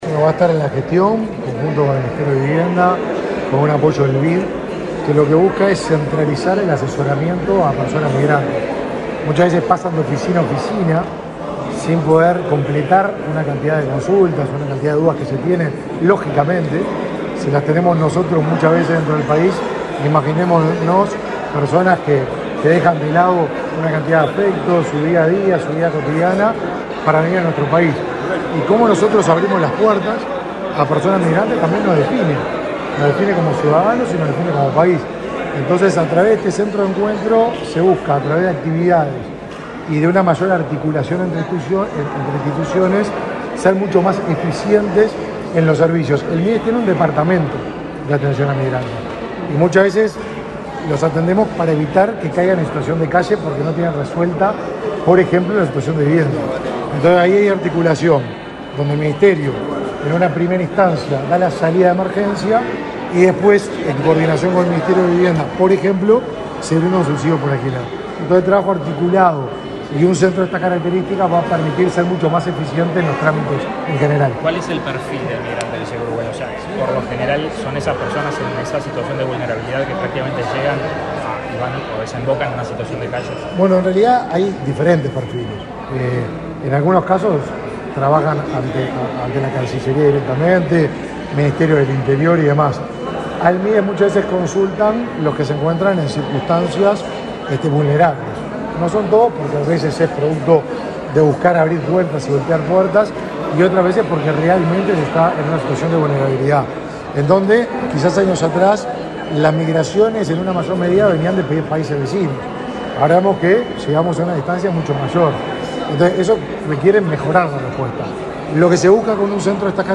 Declaraciones a la prensa del ministro de Desarrollo Social, Martín Lema
Declaraciones a la prensa del ministro de Desarrollo Social, Martín Lema 19/12/2022 Compartir Facebook X Copiar enlace WhatsApp LinkedIn El Ministerio de Vivienda y Ordenamiento Territorial y el Ministerio de Desarrollo Social firmaron un documento de creación del Centro de Apoyo para la integración Socio Urbana de la Población Migrante, este 19 de diciembre. Tras el evento, el ministro Martín Lema realizó declaraciones a la prensa.